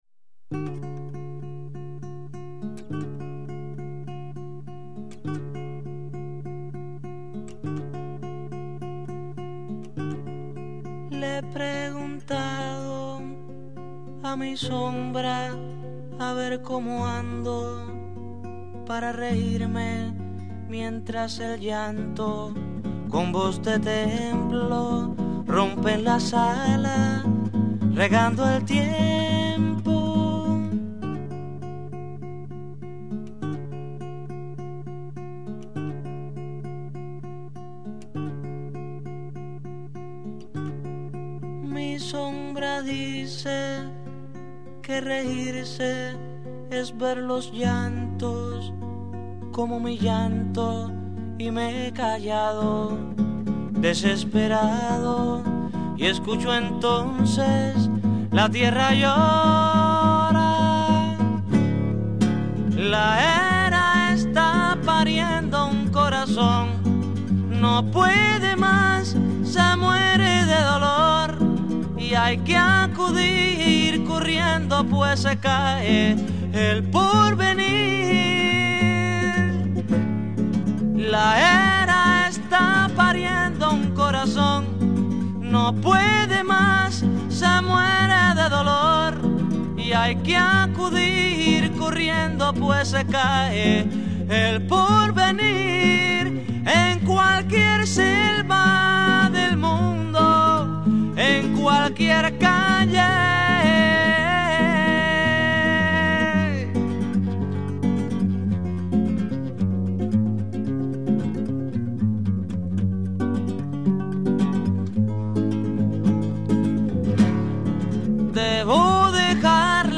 Описание: Красивая медленная песня под гитару.